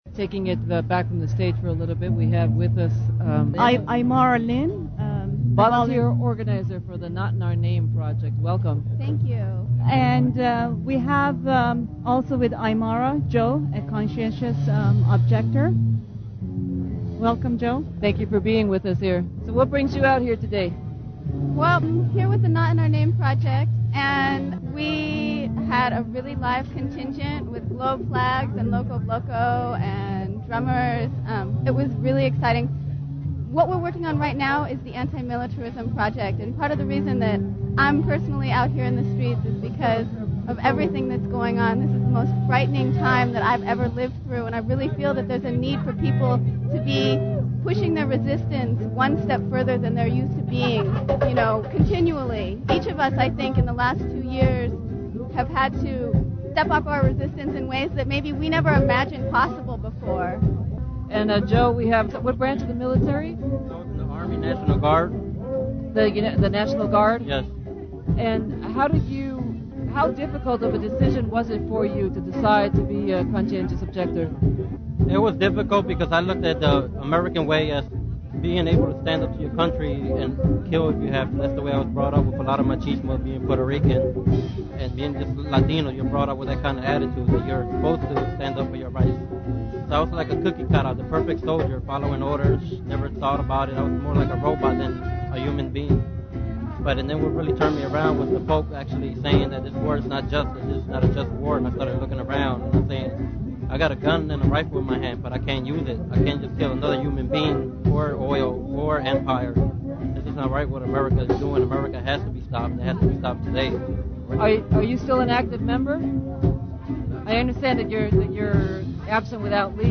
Audio from the March 20, 2004 "Global Day of Action" against occupation, San Francisco.
on KPFA Radio, broadcasting live at the rally (5:30 min)